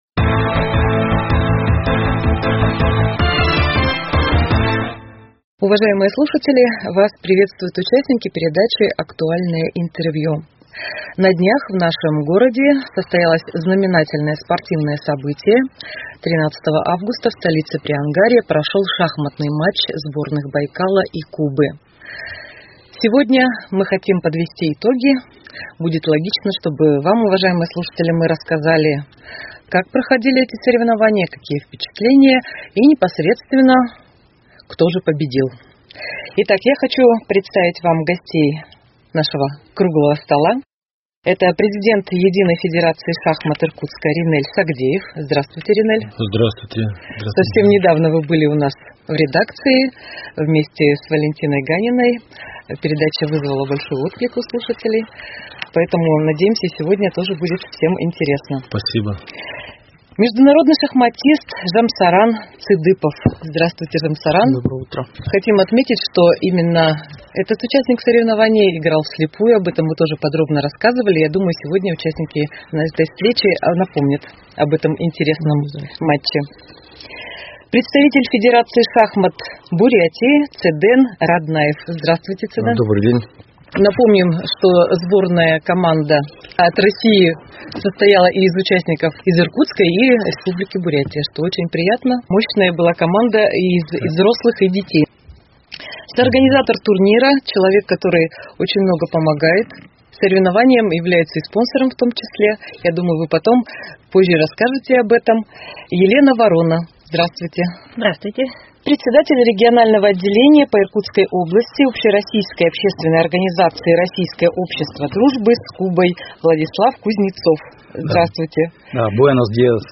Актуальное интервью: Итоги шахматного матча Байкал-Куба 17.08.2021